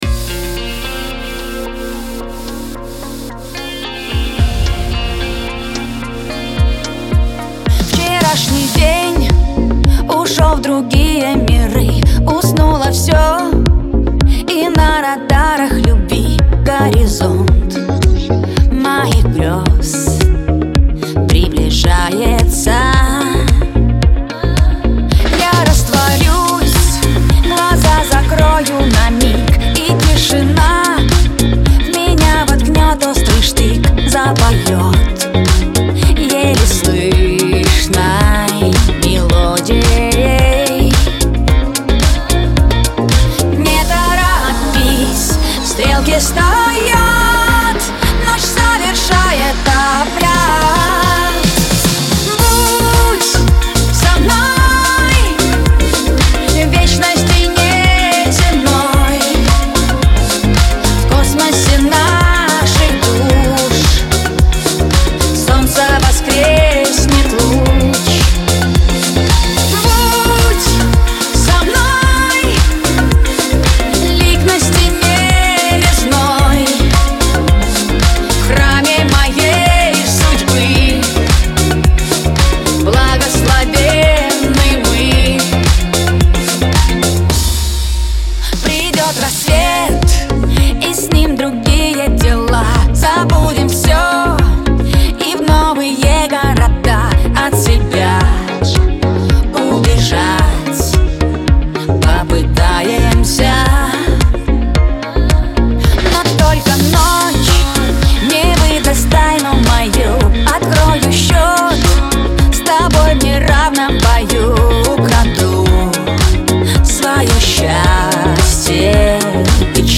трогательная поп-баллада